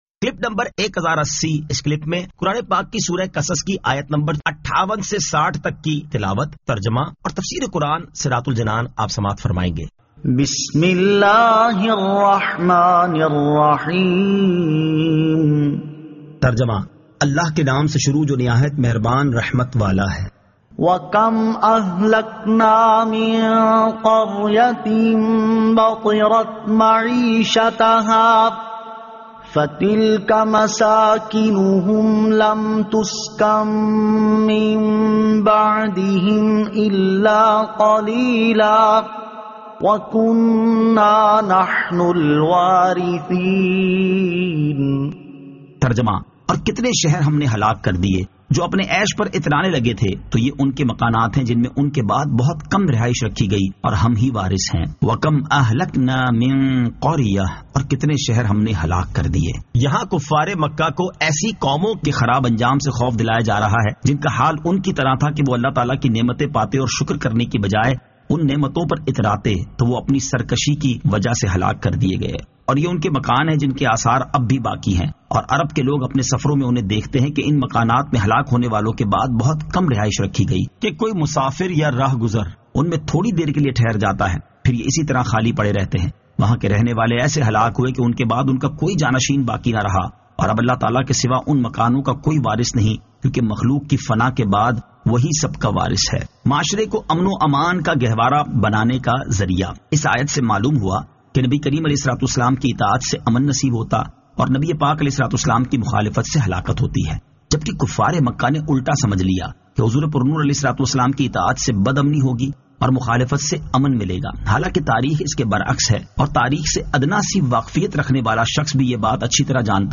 Surah Al-Qasas 58 To 60 Tilawat , Tarjama , Tafseer